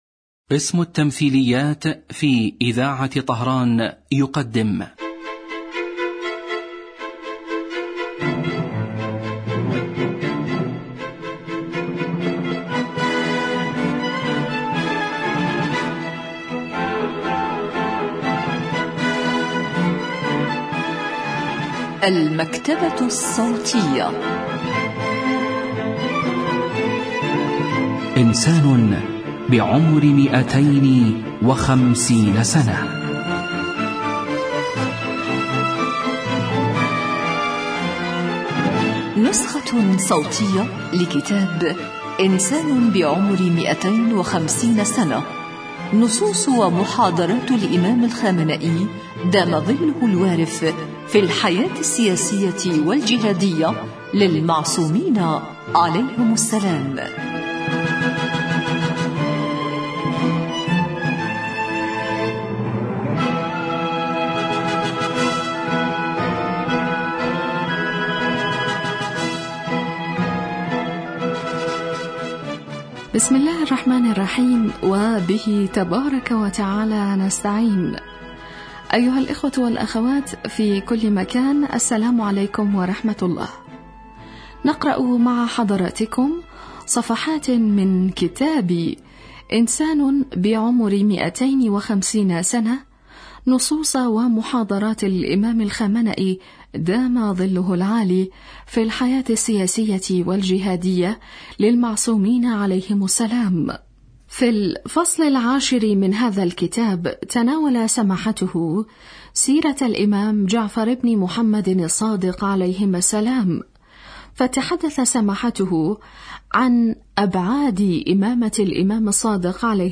الكتاب الصوتي